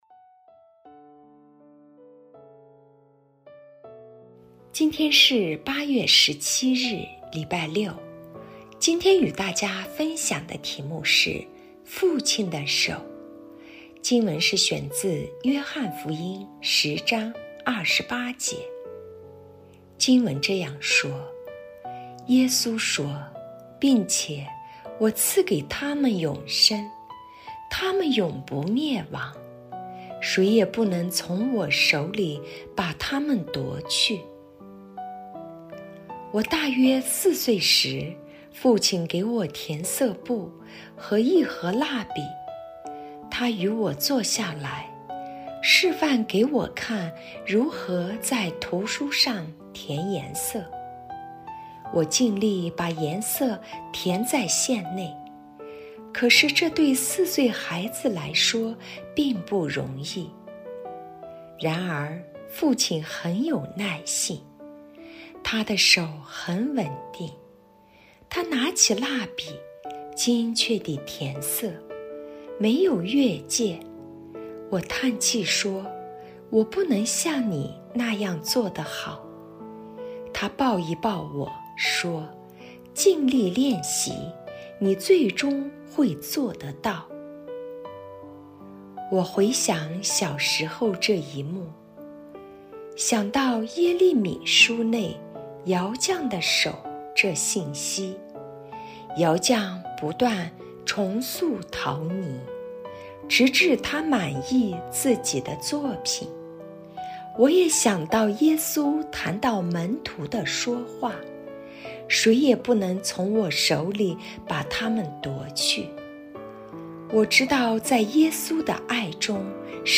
今日默想